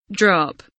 drop kelimesinin anlamı, resimli anlatımı ve sesli okunuşu